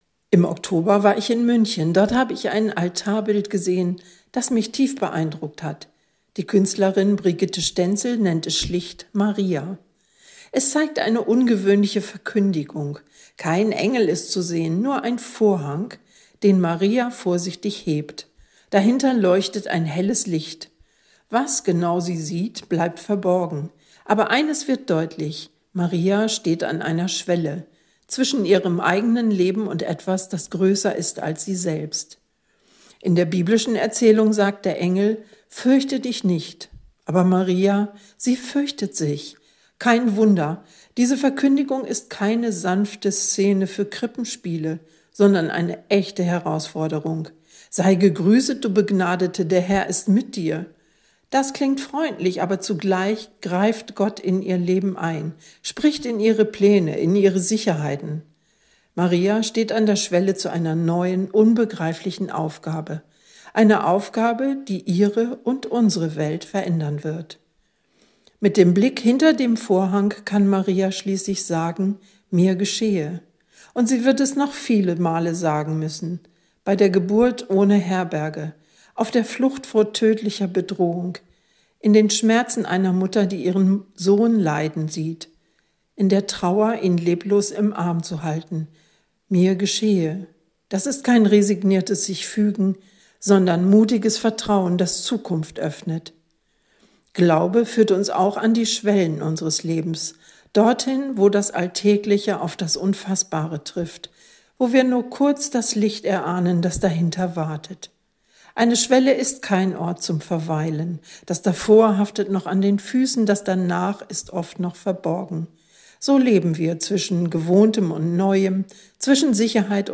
Radioandacht vom 8. Dezember